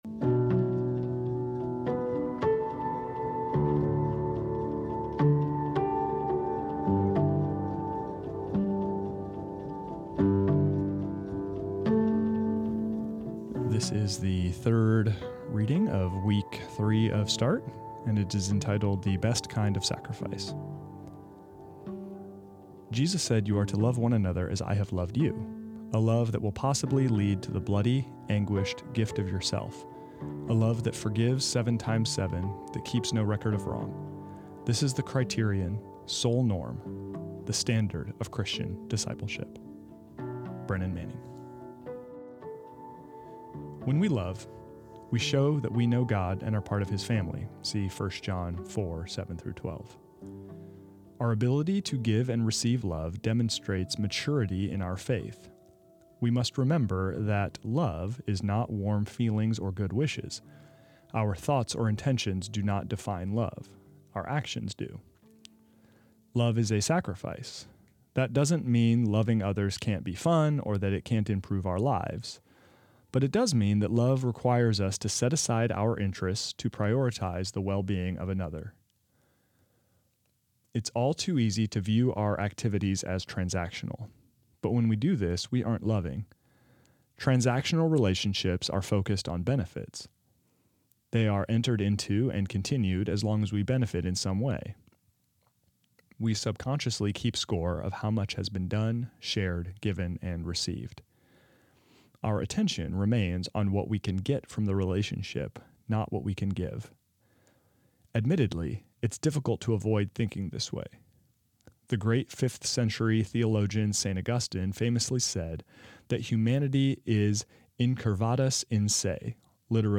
This is the audio recording of the third reading of week three of Start, entitled The Best Kind of Sacrifice.